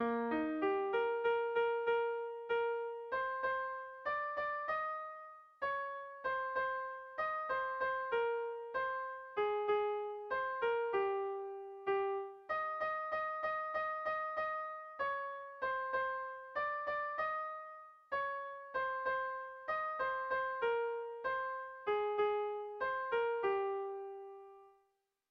Kontakizunezkoa
Erritmo interesgarrian dabil.
ABDB